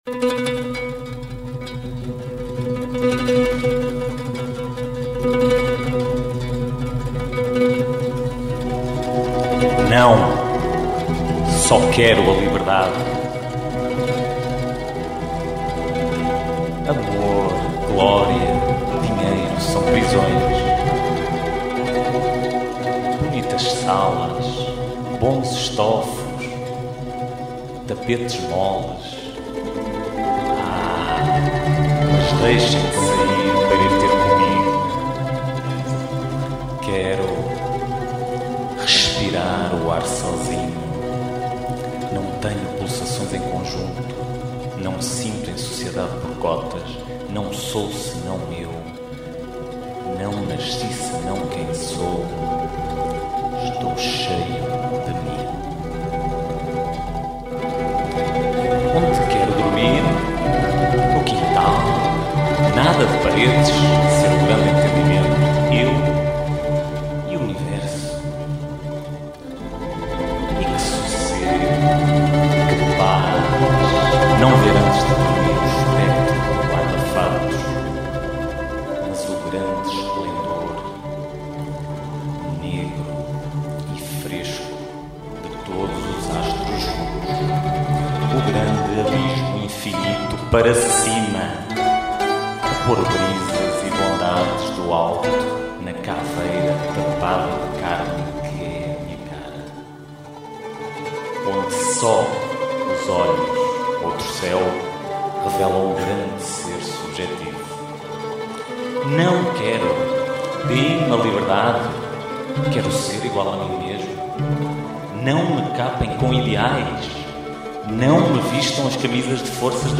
Algumas vezes confundido com o cavaquinho, o bandolim tem um som único e a particularidade de ter cordas duplas que, aliadas a uma técnica de manuseamento com muitas vibrações provocadas pelos seus executantes; lhe confere um lugar especial
XVII ComCordas - Orquestra Bandolins Esmoriz 128k.mp3